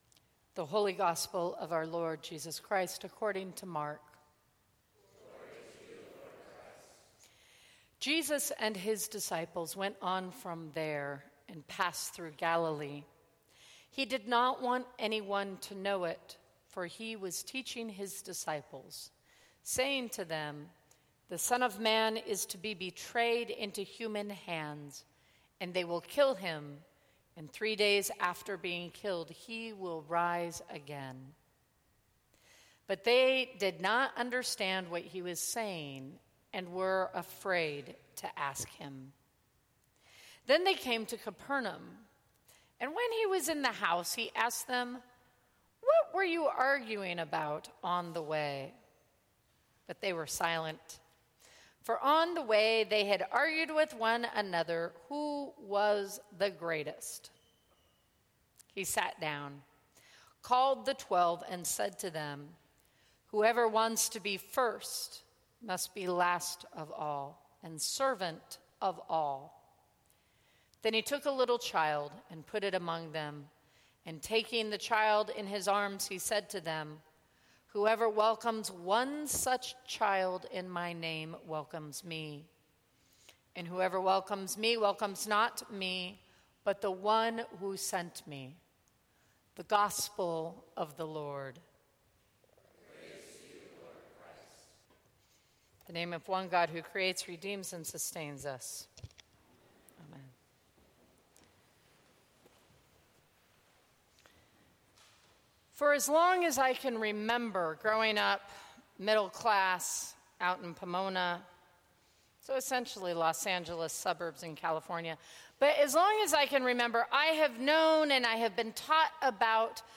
Sermons from St. Cross Episcopal Church Asking the hard questions…